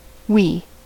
Pronunciation"wee"
Oey (pronounced /w/; like "wee"
En-us-wee.ogg.mp3